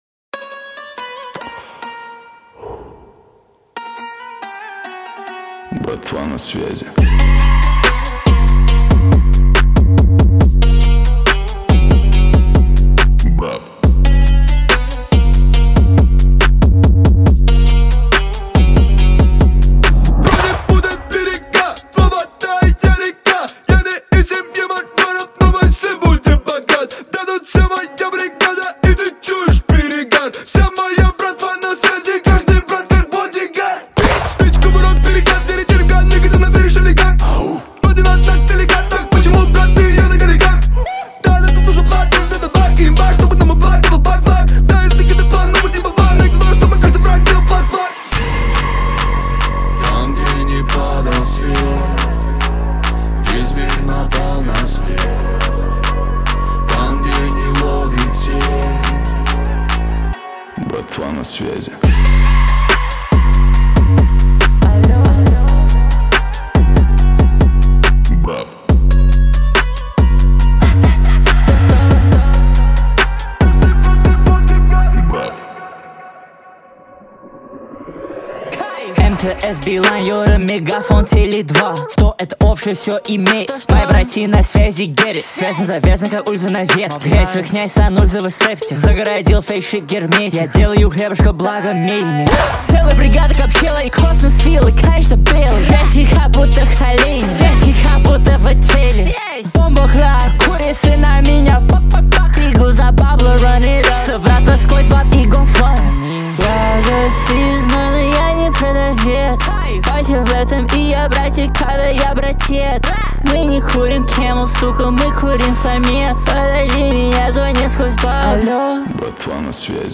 دانلود آهنگ روسی فاز دار